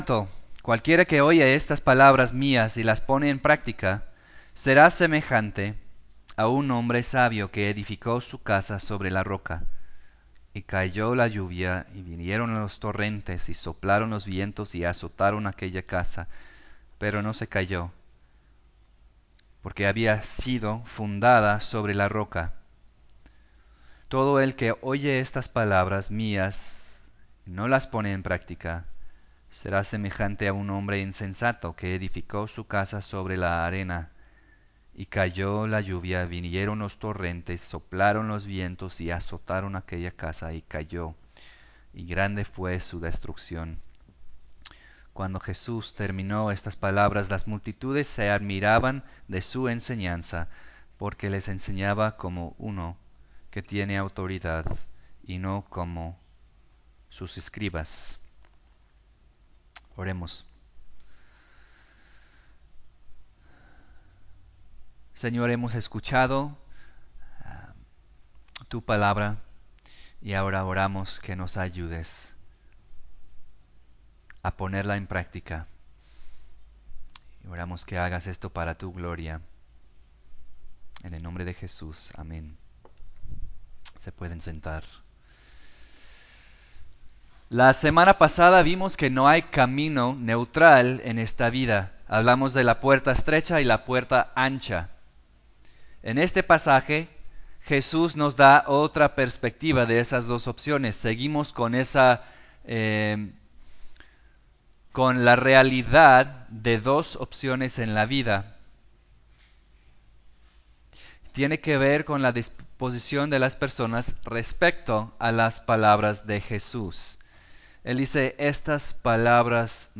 Sermon-del-Monte-16.mp3